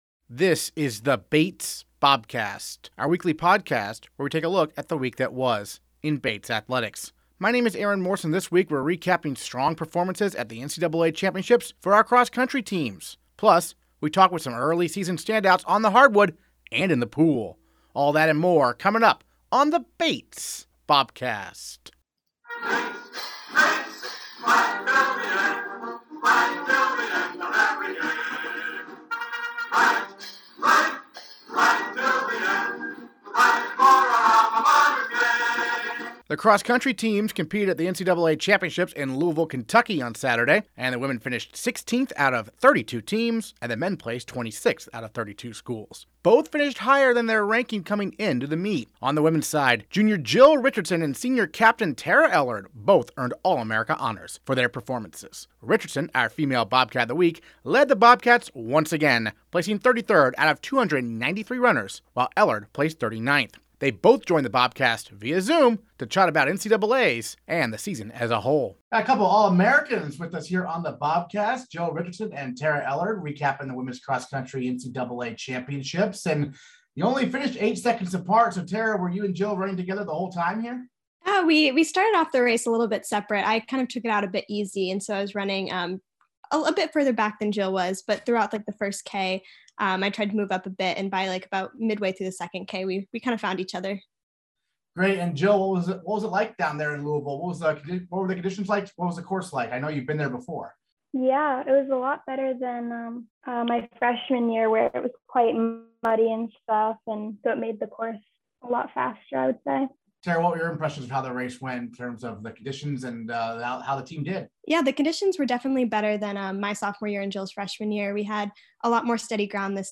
On the latest Bobcast, we're recapping strong performances at the NCAA Championships for our cross country teams! Plus, we talk with some early season standouts on the hardwood and in the pool.